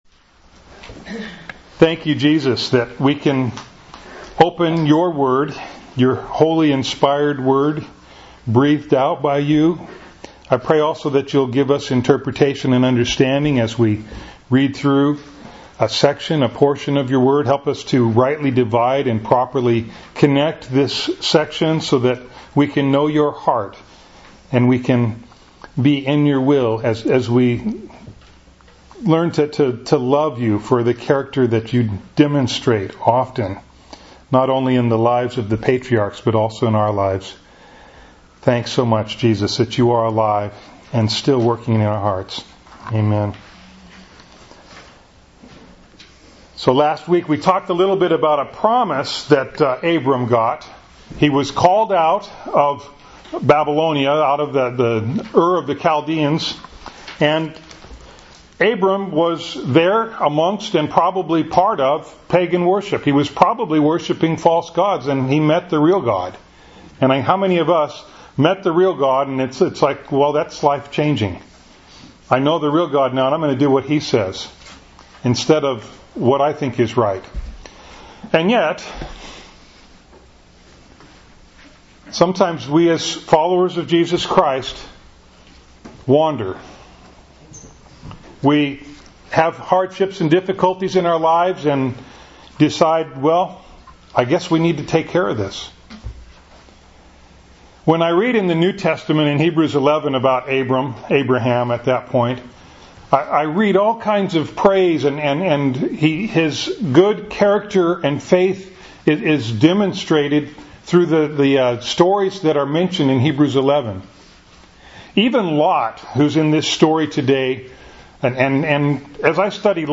Bible Text: Genesis 12:10-13:18 | Preacher